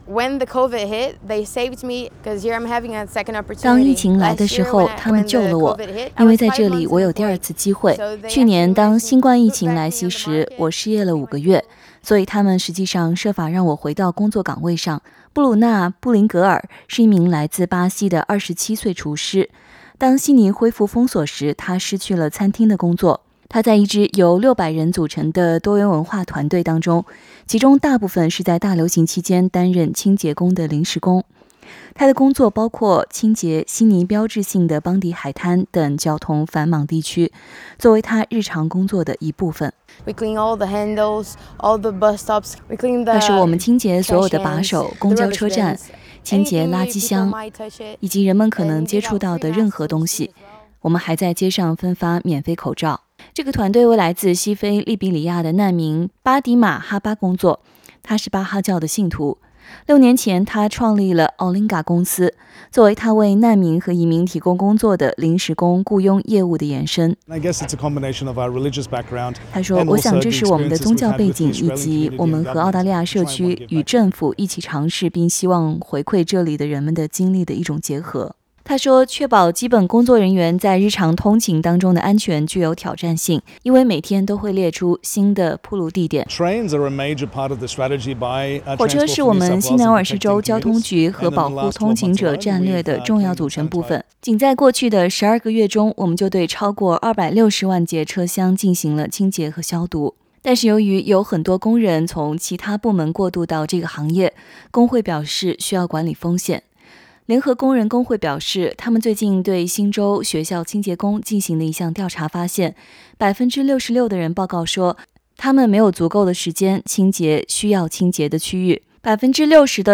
在澳大利亞最近的封鎖期間，清潔工比以往任何時候都更加忙碌，對暴露於新冠病毒的場所進行消毒。本週，系列訪談中寀訪了一家清潔公司，該公司也為在其他行業失去工作的工人提供了一些工作機會。